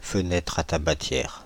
Ääntäminen
Ääntäminen France (Île-de-France): IPA: /fə.nɛtʁ a ta.ba.tjɛʁ/ Haettu sana löytyi näillä lähdekielillä: ranska Käännöksiä ei löytynyt valitulle kohdekielelle.